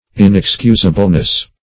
Search Result for " inexcusableness" : The Collaborative International Dictionary of English v.0.48: Inexcusableness \In`ex*cus"a*ble*ness\, n. The quality of being inexcusable; enormity beyond forgiveness.